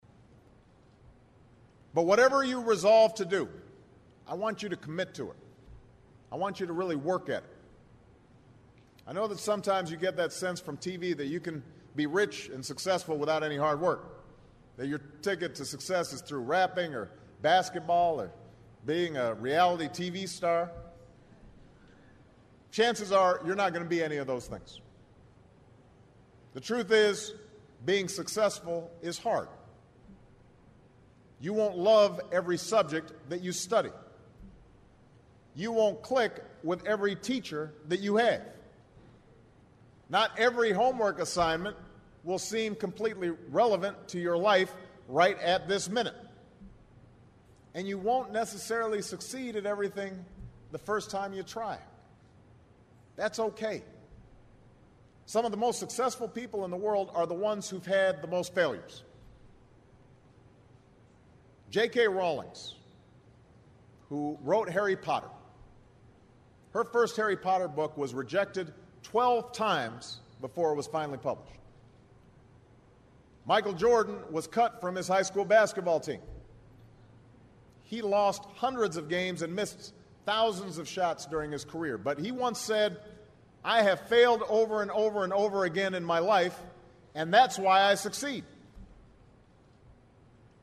名人励志英语演讲 第8期:梦想与责任(8) 听力文件下载—在线英语听力室